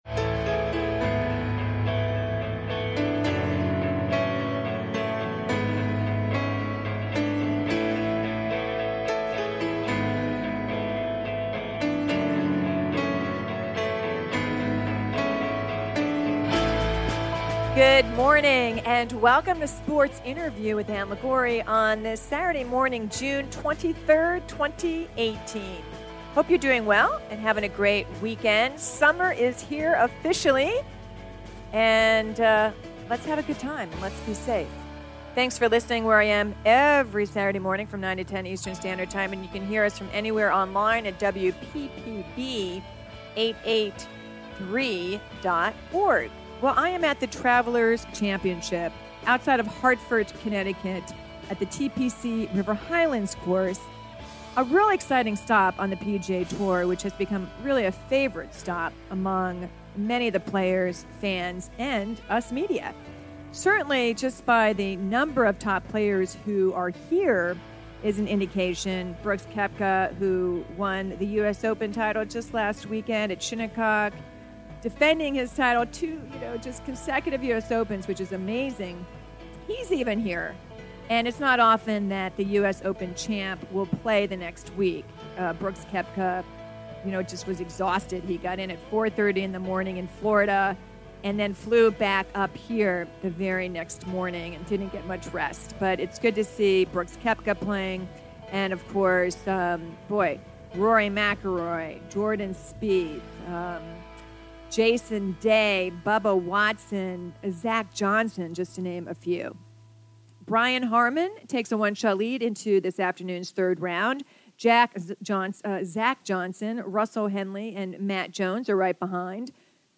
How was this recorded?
from The Travelers Championship, this week’s PGA Tour stop in Hartford, Conn.